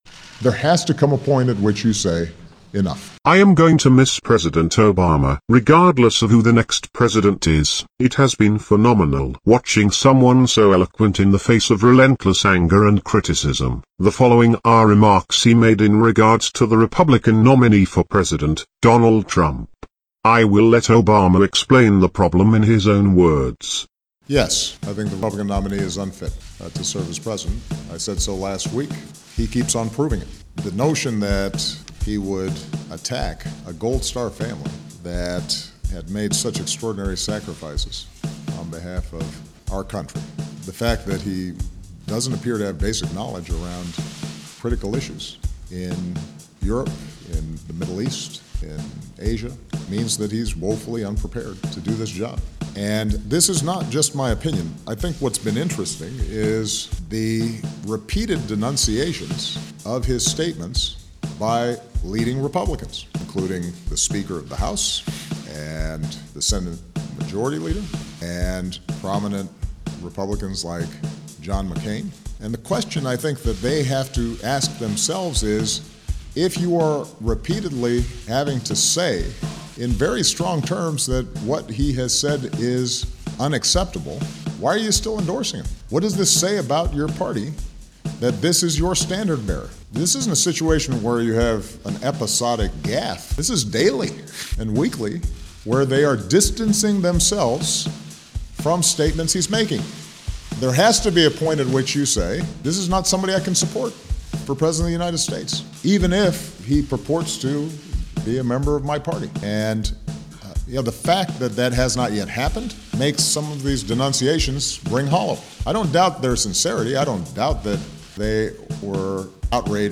I made a mp3 song/message, one in which Obama is featured giving remarks on Donald Trump during a press conference on Aug 2nd. I edited it 70 times to remove the ‘umms,’ and silences from his comments.